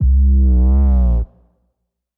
C_808 Slide.wav